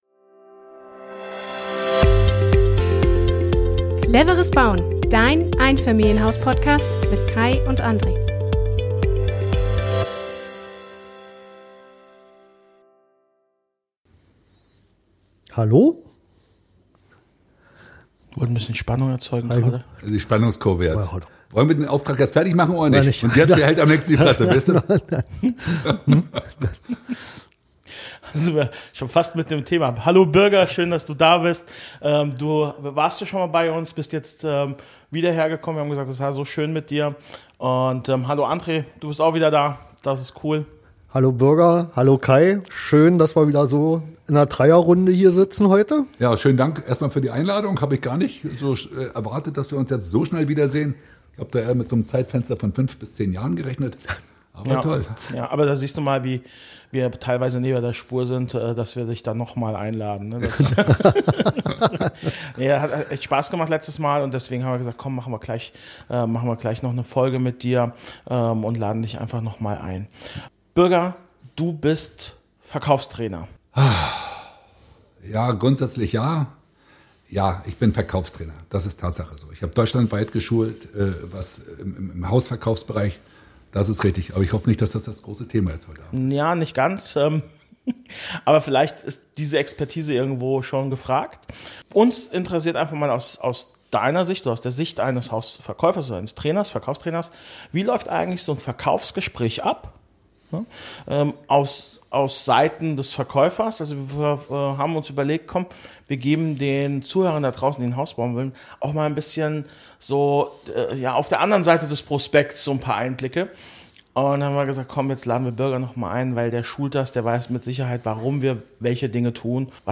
Wir plaudern mit ihm ganz locker über das, was beim Hauskauf wirklich hinter den Kulissen passiert.